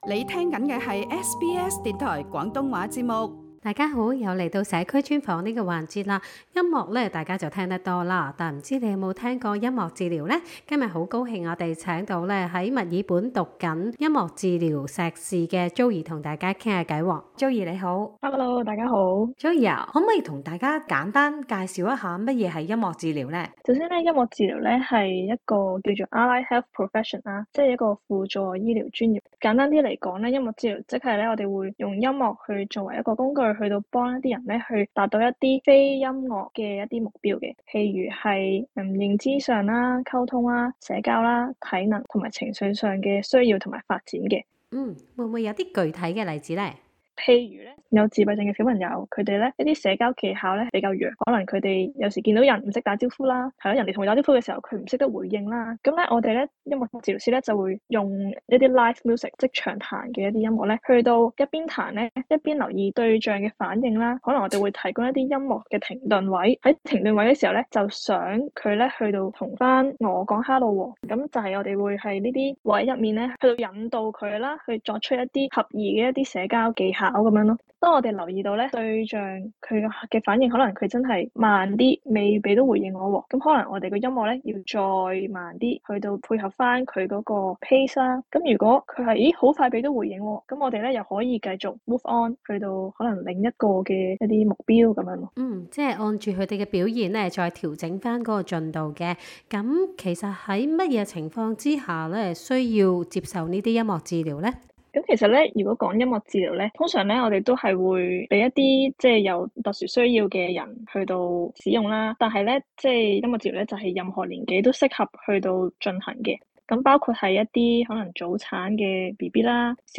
cantonese_communityinterview_1007_drupal.mp3